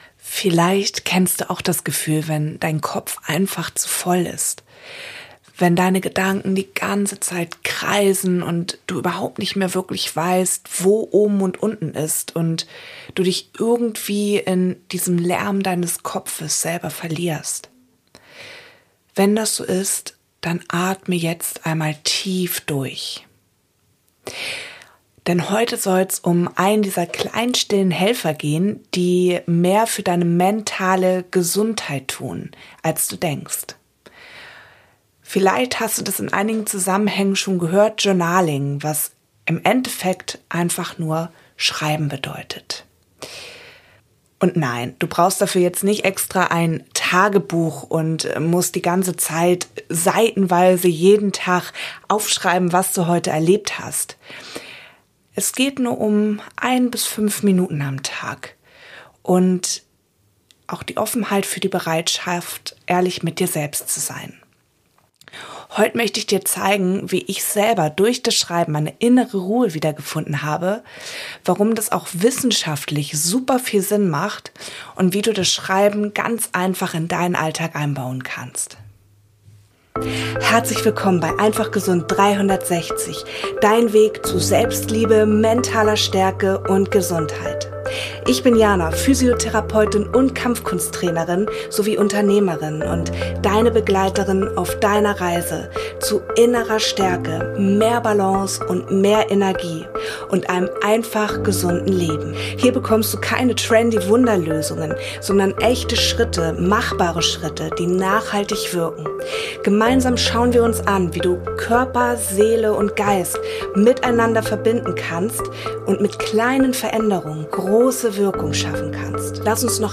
Audition Template: 24 Mono tracks routed to a Stereo Master. 44.1k, 24 bit, Stereo Master.